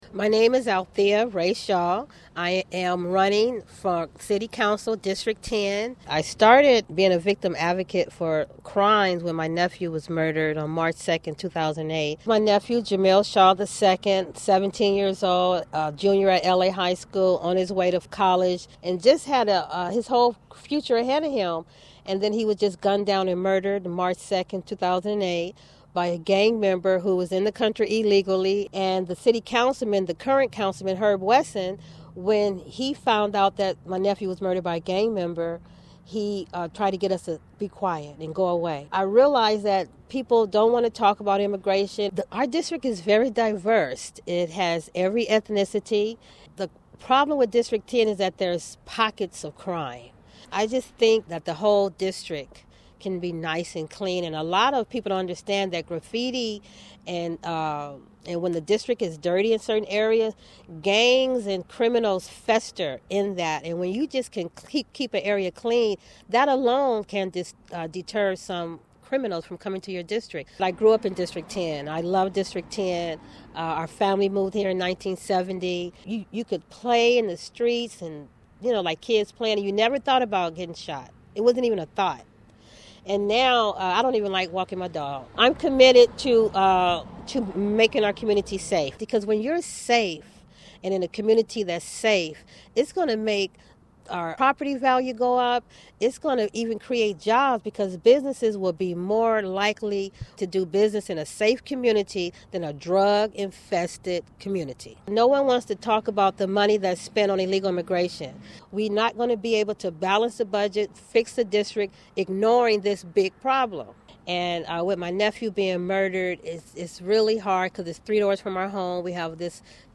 Contributing reporter
interviews